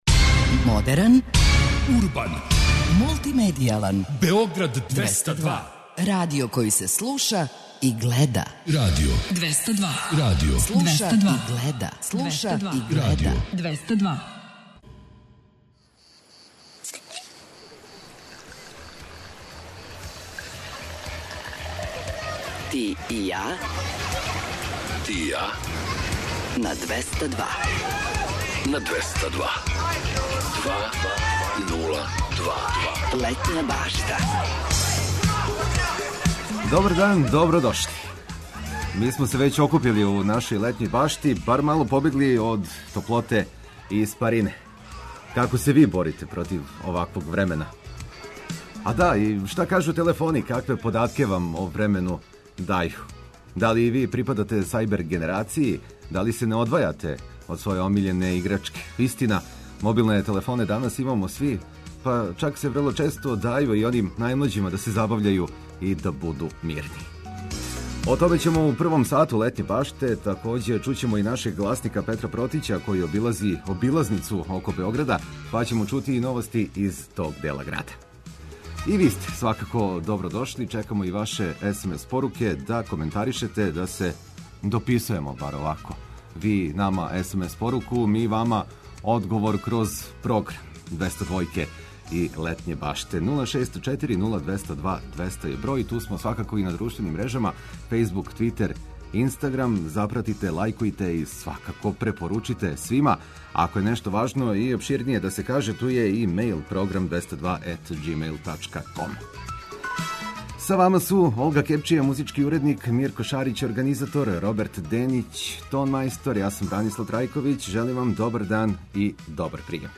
За боље организовање дана ту су вести и друге важне информације. Зачин пријатном дану биће ведра музика, лепе вести и водич кроз текуће манифестације у Србији.